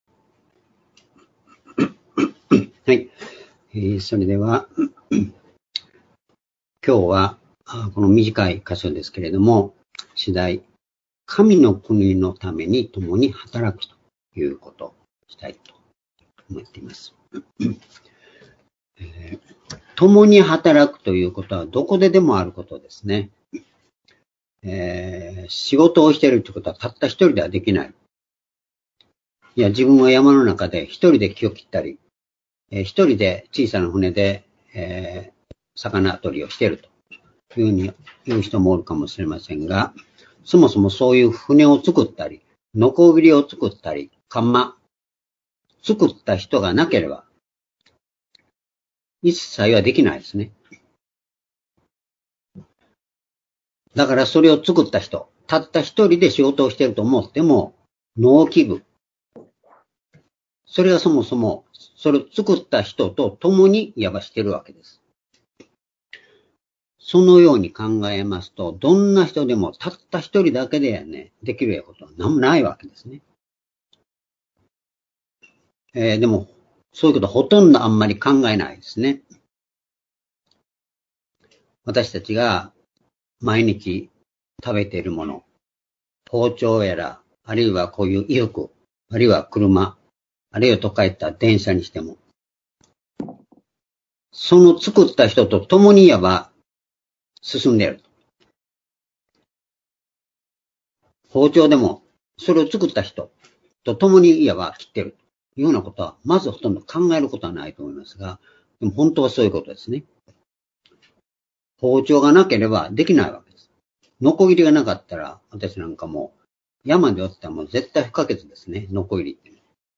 「神の国のためにともに働く」コロサイ書4章9節～13節-2021年8月1日（主日礼拝）